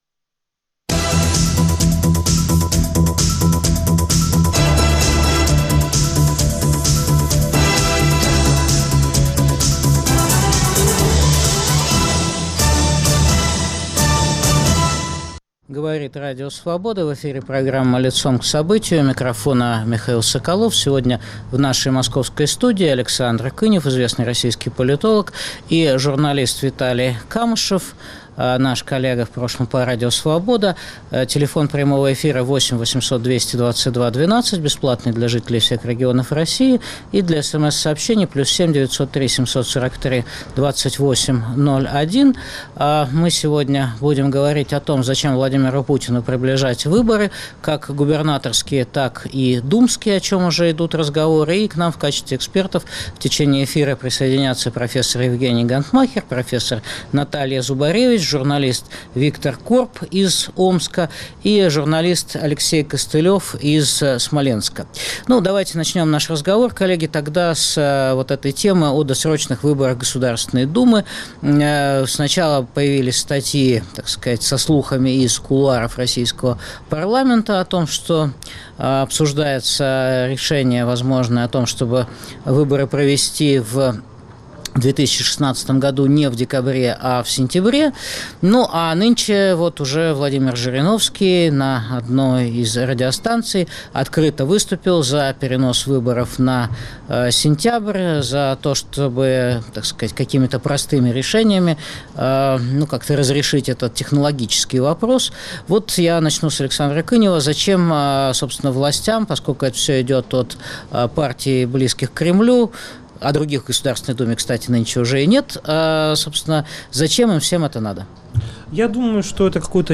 Обсуждают